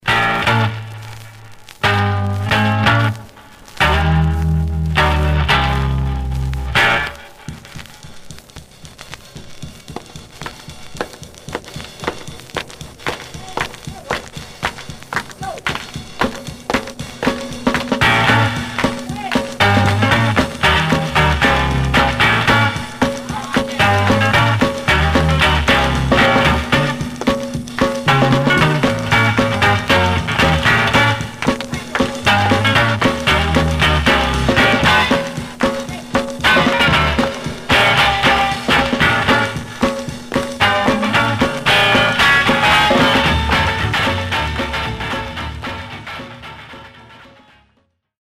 Stereo/mono Mono
R & R Instrumental Condition